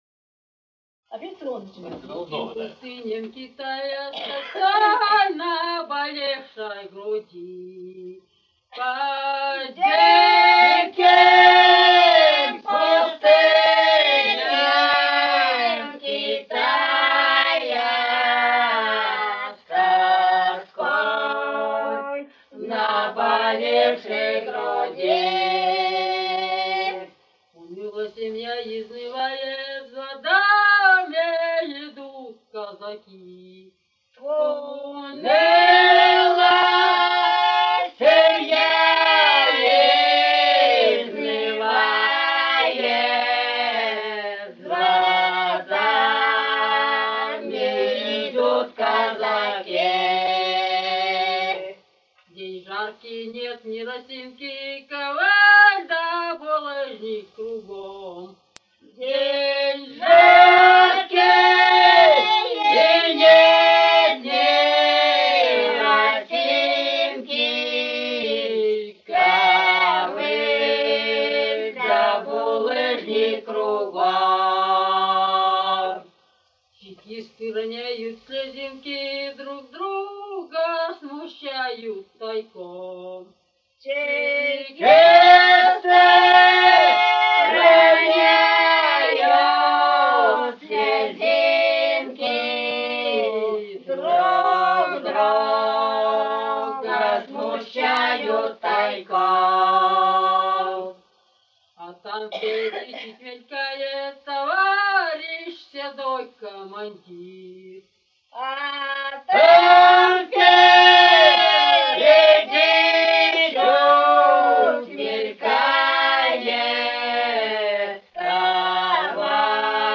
Русские песни Алтайского Беловодья «По диким пустыням Китая», лирическая казачья.
с Урыль Катон-Карагайского р-на Восточно-Казахстанской обл.